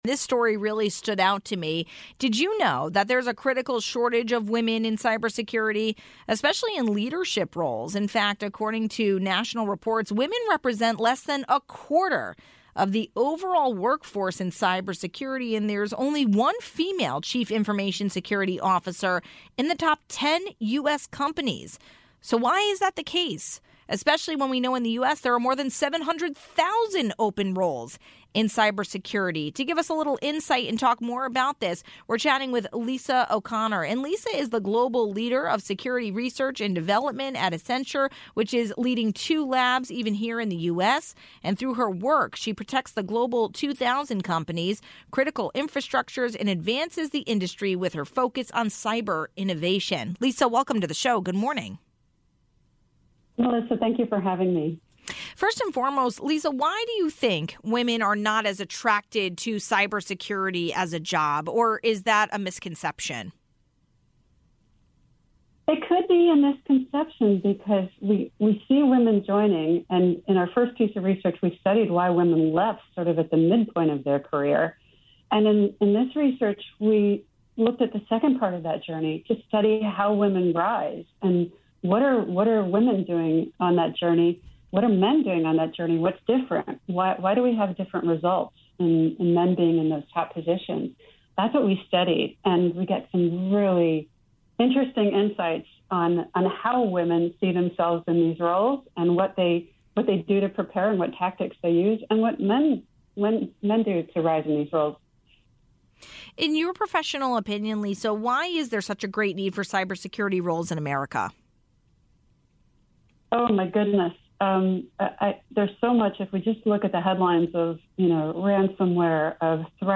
((00:00)) This week is fire prevention week and we spoke with Bridgeport Fire Chief Lance Edwards about what you can do to best prepare for a potential house fire. ((12:07)) We wrap up our coverage for breast cancer awareness month, talking about breast health and reconstruction surgery.